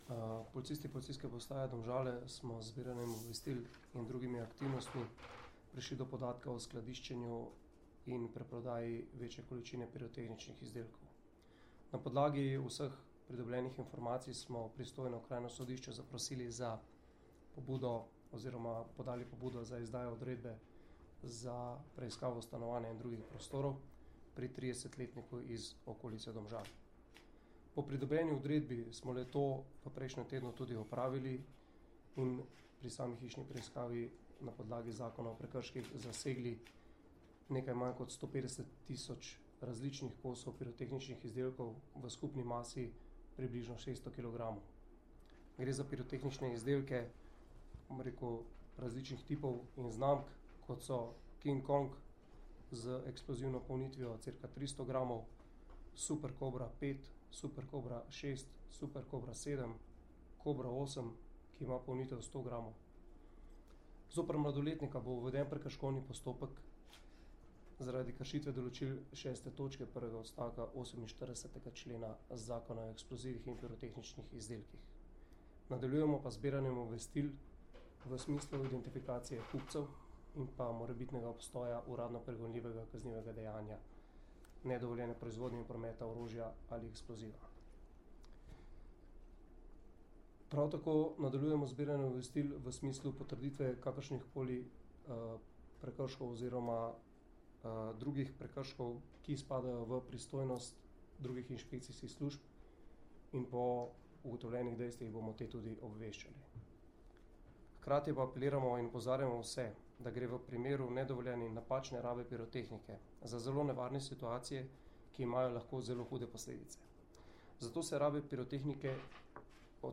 Več o zasegih sta na današnji novinarski konferenci povedala
Zvočni posnetek izjave